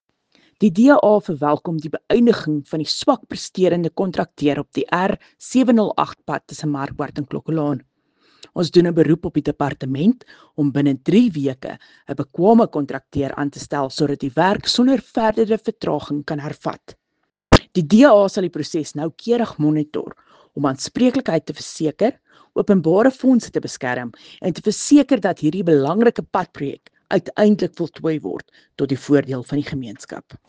Afrikaans soundbites by Cllr Riëtte Dell and